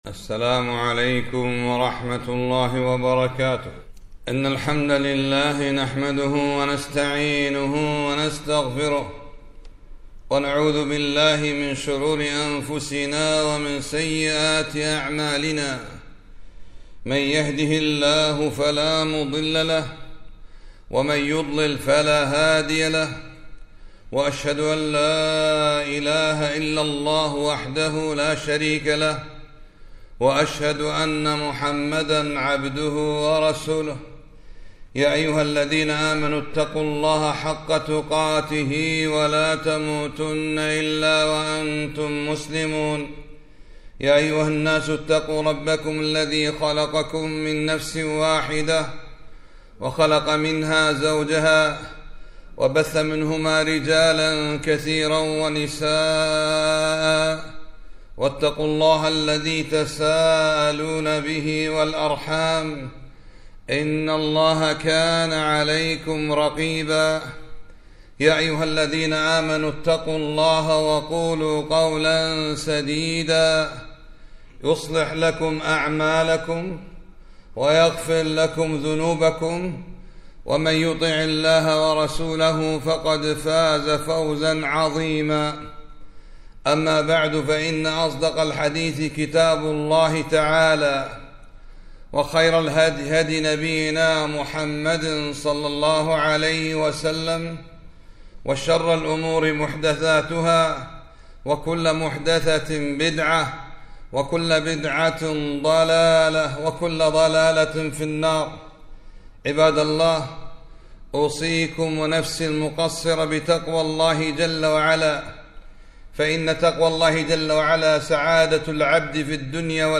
خطبة - لا تؤذوا رسول الله في عرضهِ